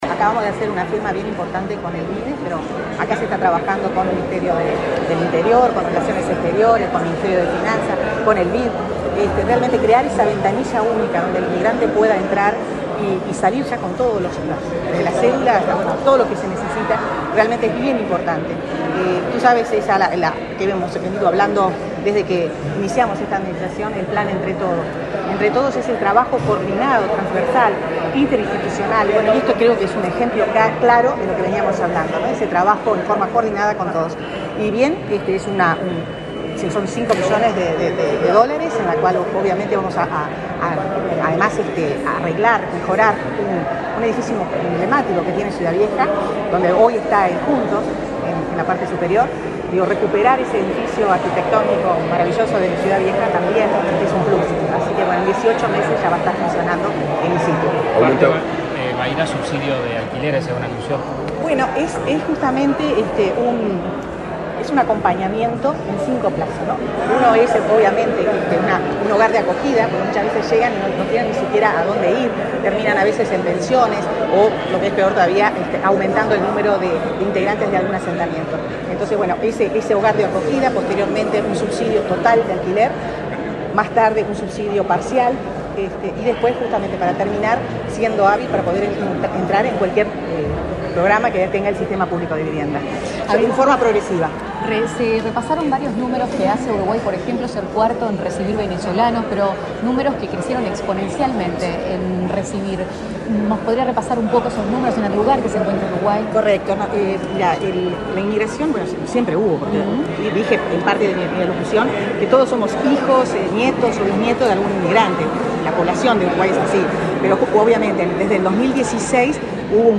Declaraciones de prensa de la ministra de Vivienda y Ordenamiento Territorial, Irene Moreira
Declaraciones de prensa de la ministra de Vivienda y Ordenamiento Territorial, Irene Moreira 19/12/2022 Compartir Facebook X Copiar enlace WhatsApp LinkedIn Este 19 de diciembre los ministerios de Vivienda y Ordenamiento Territorial y de Desarrollo Social firmaron el documento por el que se creó el Centro de Apoyo para la Integración Socio Urbana de la Población Migrante. Tras el evento, la ministra Irene Moreira realizó declaraciones a la prensa.